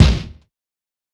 PBJ Kick - Cent.wav